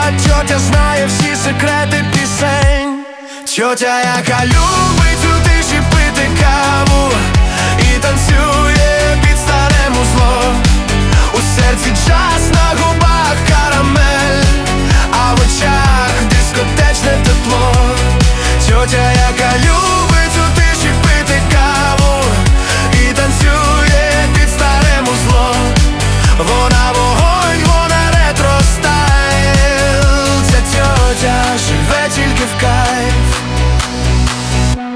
Жанр: Поп музыка / Украинские